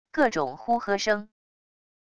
各种呼喝声wav音频